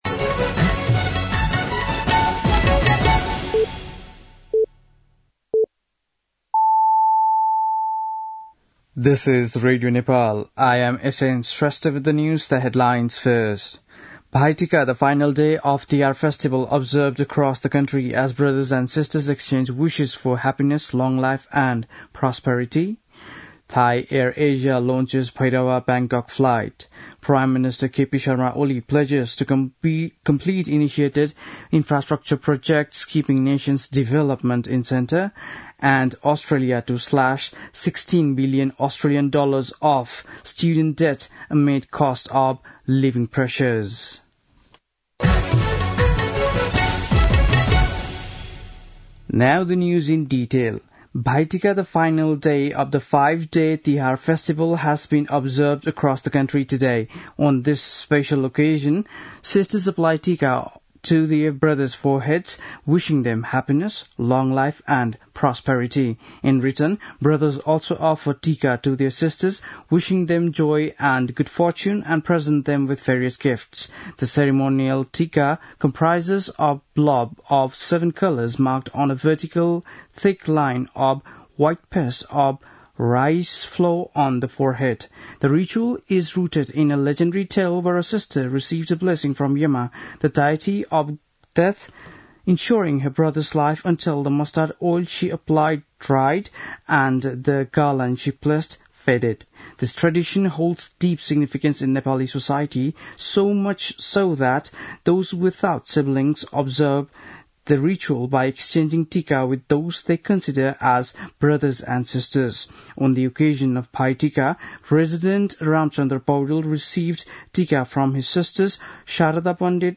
An online outlet of Nepal's national radio broadcaster
बेलुकी ८ बजेको अङ्ग्रेजी समाचार : १९ कार्तिक , २०८१